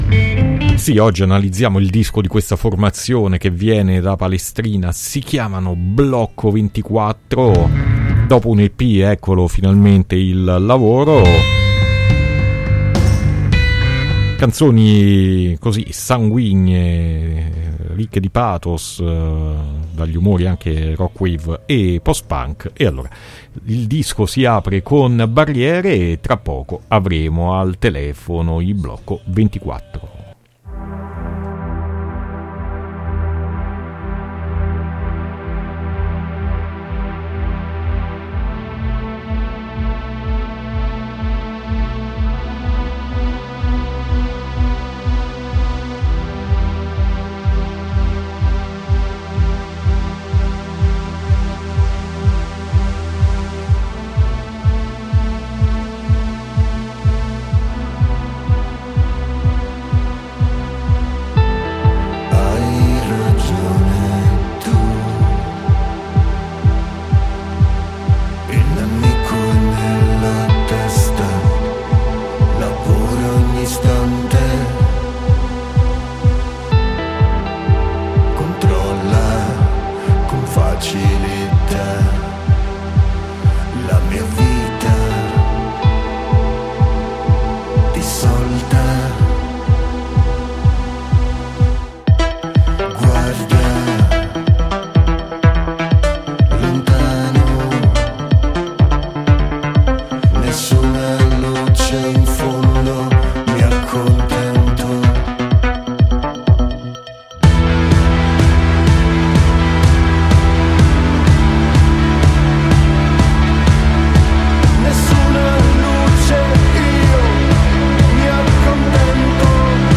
La formula dei Blocco 24 è un rock sanguigno, viscerale e poetico che ama citazioni post punk e new wave.
intervista-blocco24.mp3